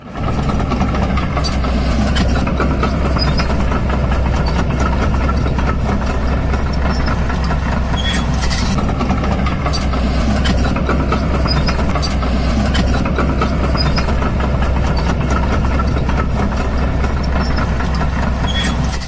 Tiếng xe Máy Múc, Đào, Xúc… bánh xích di chuyển
Thể loại: Tiếng xe cộ
Khi máy múc, máy đào hoặc máy xúc bánh xích di chuyển, chúng phát ra tiếng động cơ nổ mạnh mẽ cùng với tiếng ồn của bánh xích lăn trên mặt đất.
tieng-xe-may-muc-dao-xuc-banh-xich-di-chuyen-www_tiengdong_com.mp3